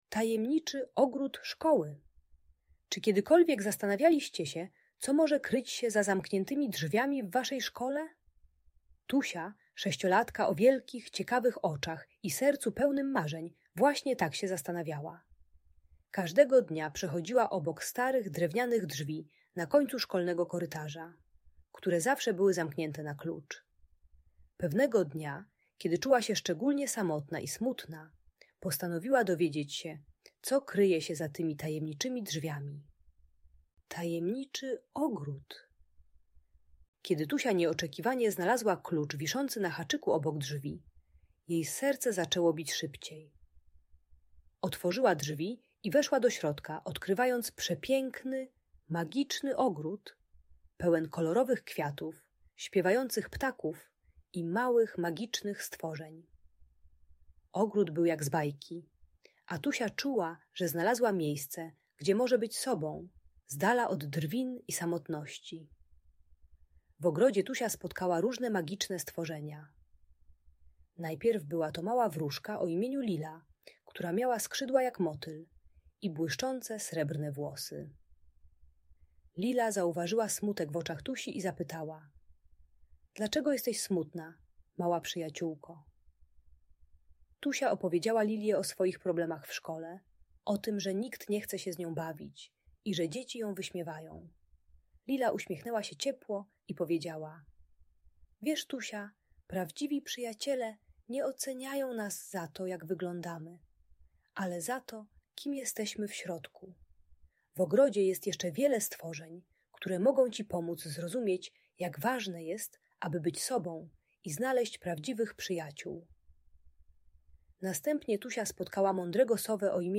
Tajemniczy Ogród Szkoły - Lęk wycofanie | Audiobajka